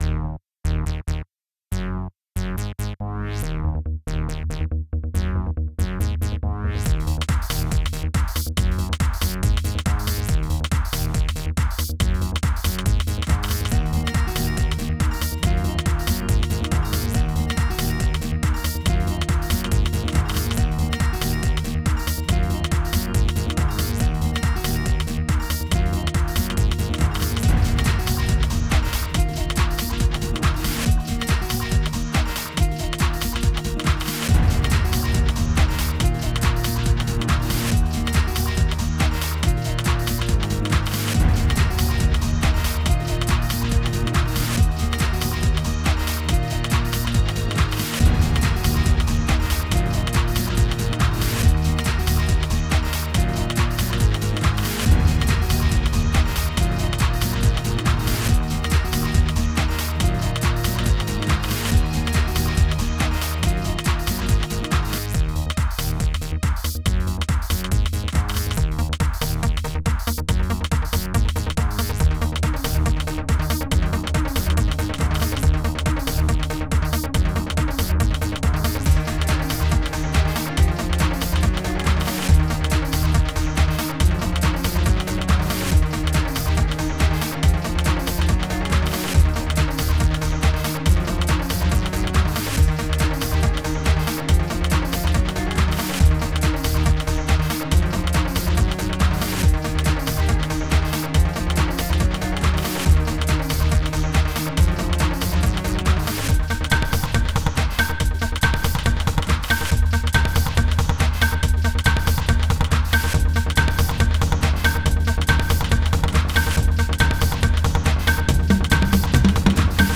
Genre:   Free music - Dance Floor, House, Trance